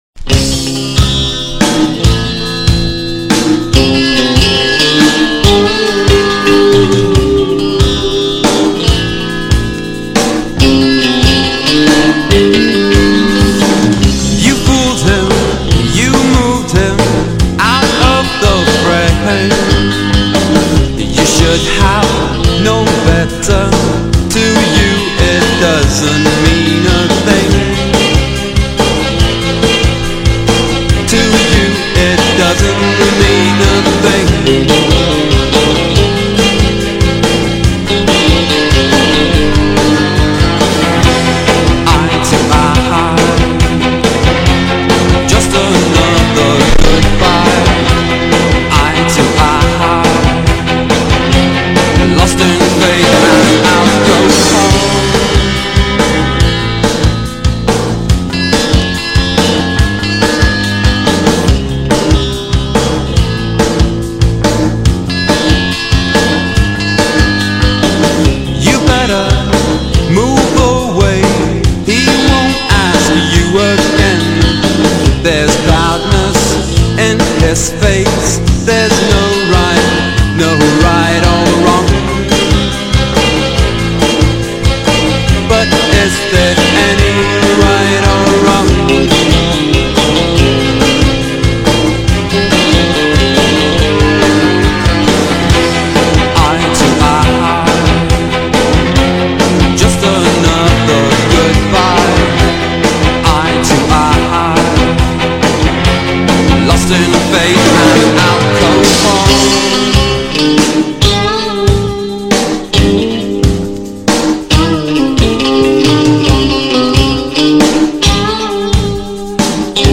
indiepop
vocals
guitar
bass
drums
strings